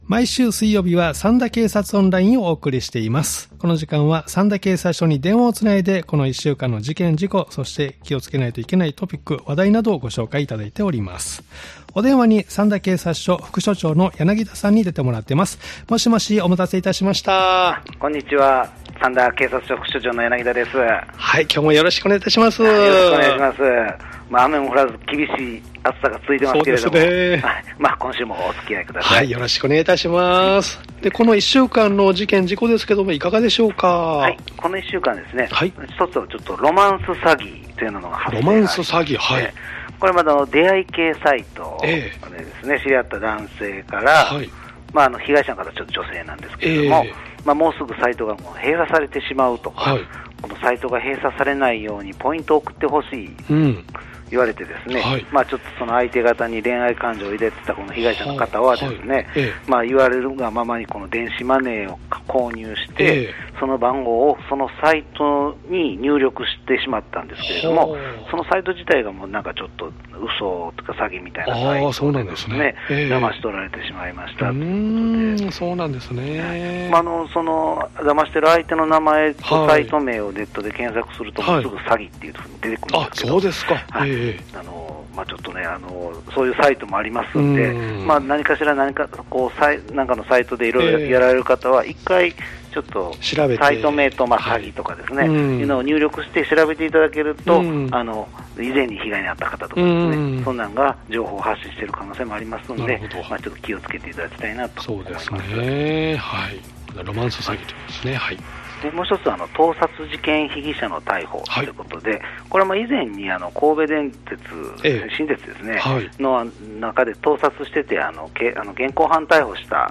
三田警察署に電話を繋ぎ、三田で起きた事件や事故、防犯情報、警察からのお知らせなどをお聞きしています（再生ボタン▶を押すと番組が始まります）